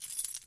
房屋钥匙 " 钥匙10
描述：我的房子钥匙用我的se2200a记录了
Tag: 拨浪鼓 房子 钥匙 se2200